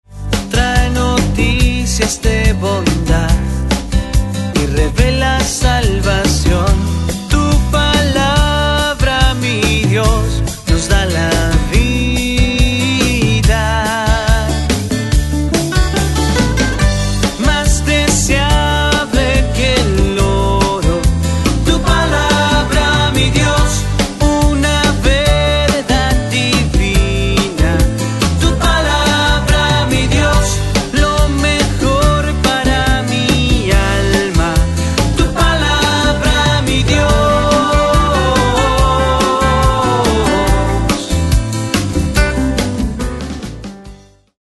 Guitarra - Tono: GHoja de Partitura - Tono: G